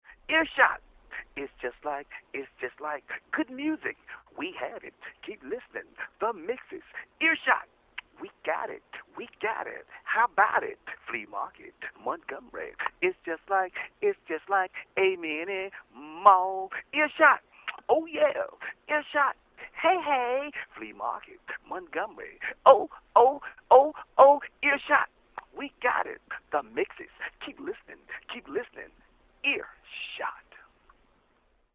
on the spot – no rehearsing!